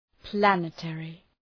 Προφορά
{‘plænı,terı}